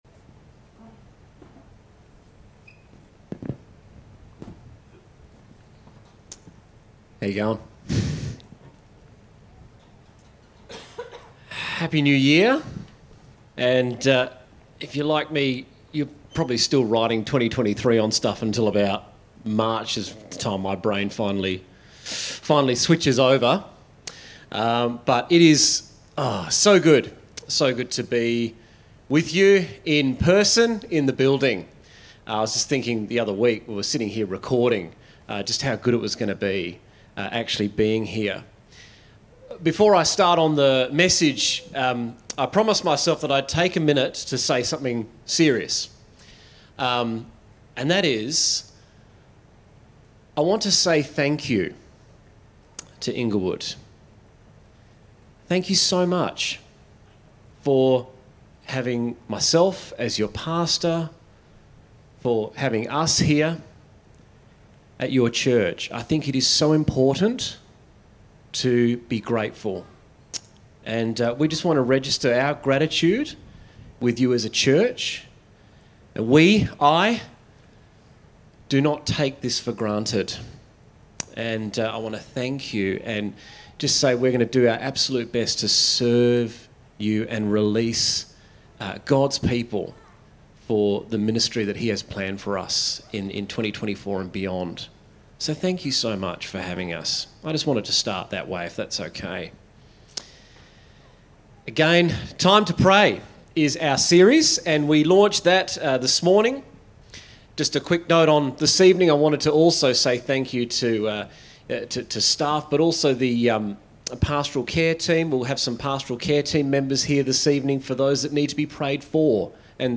A message from the series "Time to Pray."